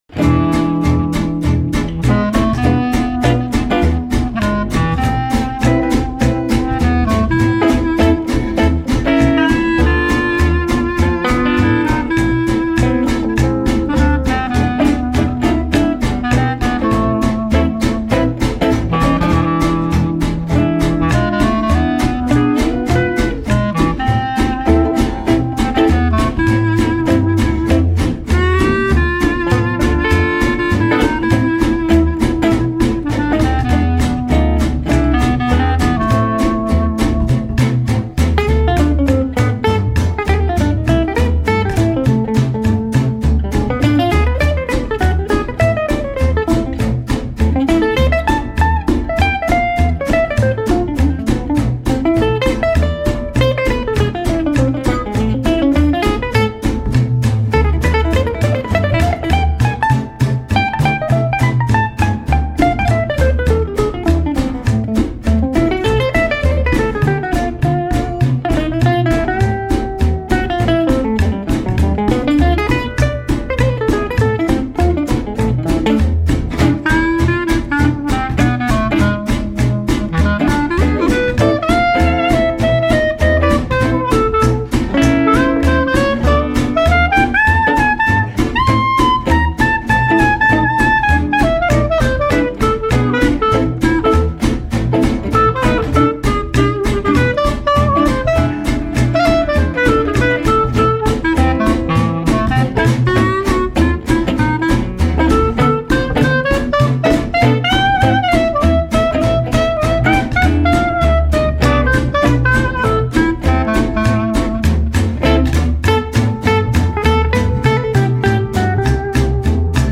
in a very traditional style. Recorded in Austin back in 2001